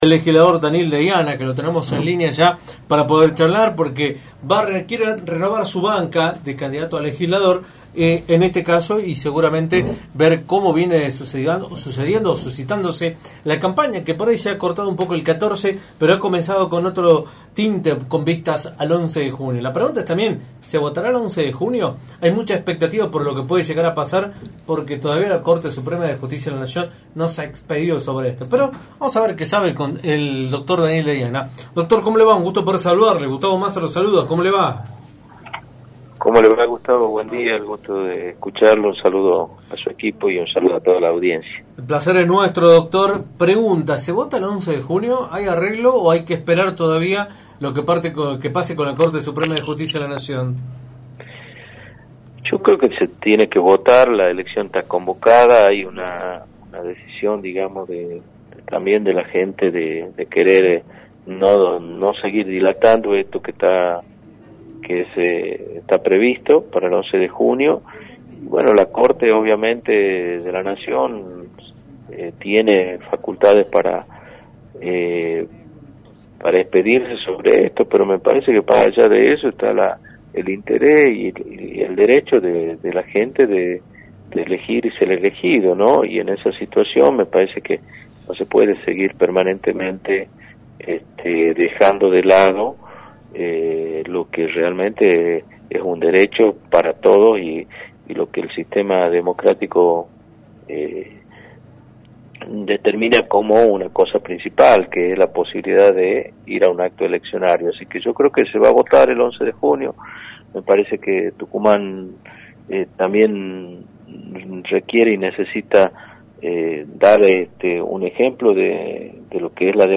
Daniel Deiana, Legislador y candidato a renovar su banca en el parlamento tucumano, analizó en Radio del Plata Tucumán, por la 93.9, el escenario electoral de la provincia, a menos de una semana de los comicios establecidos para el próximo domingo.